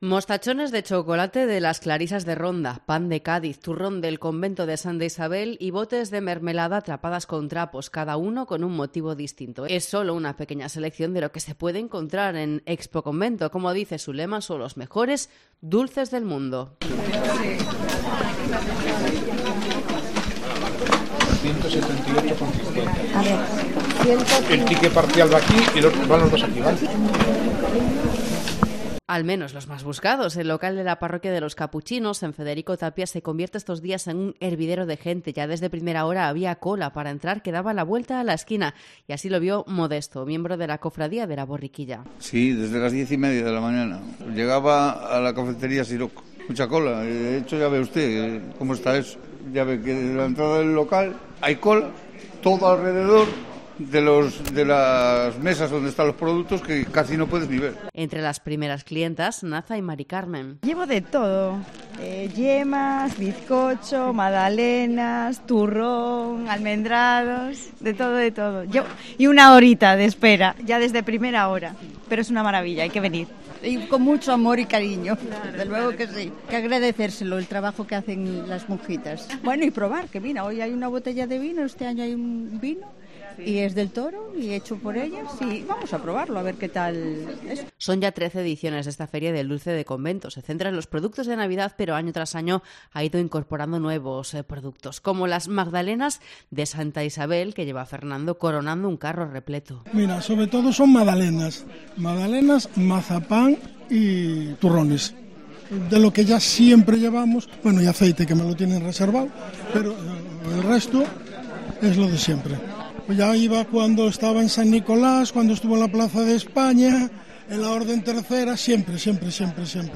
Crónica de Expoconvento 2023